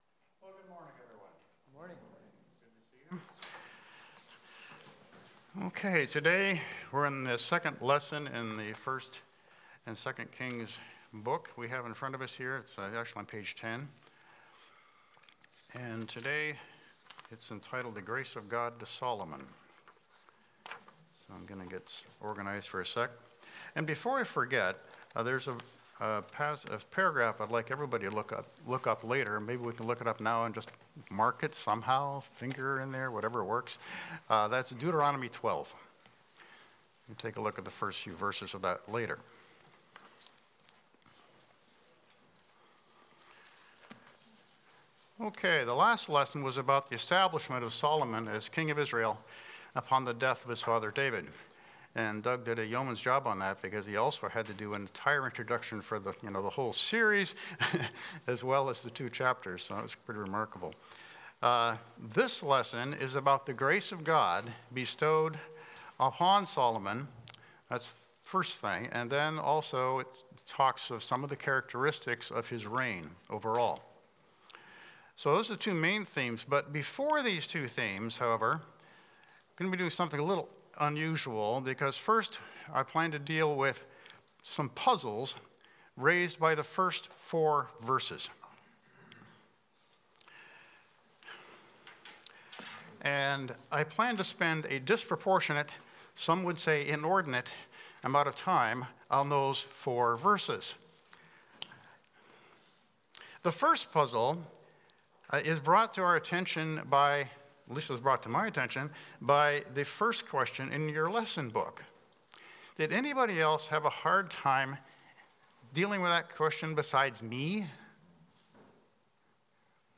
Passage: 1 Kings 3-4 Service Type: Sunday School